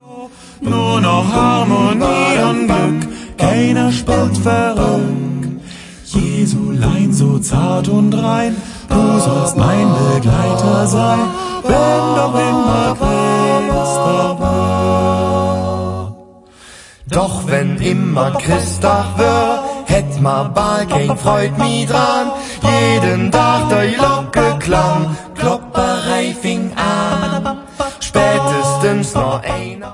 Weihnachtsleedcher und Verzällcher in Kölscher Mundart